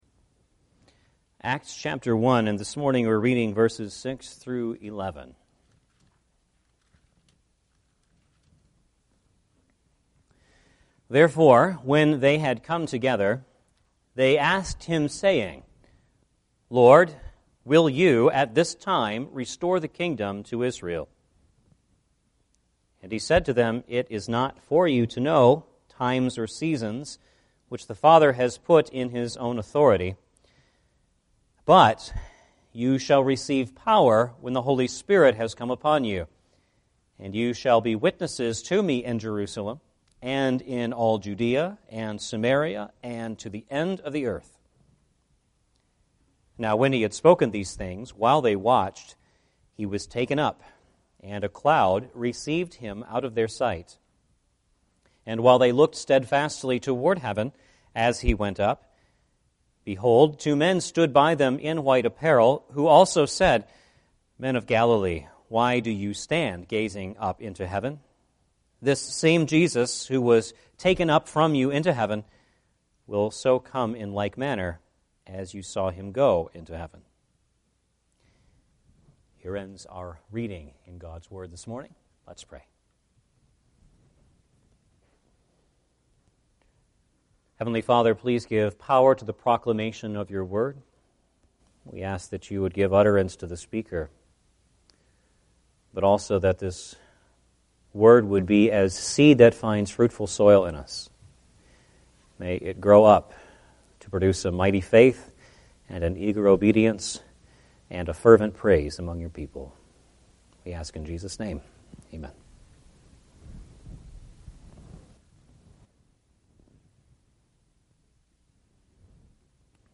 Acts 1:6-11 Service Type: Sunday Morning Service « Why I Love the OPC